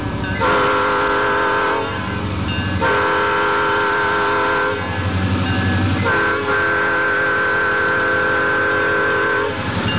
Horn bells cast from both the new and old patterns have found their way onto new P5's in various combinations, resulting in a wide variety of different, often dischordant, sounds.
Norfolk Southern, various locomotives: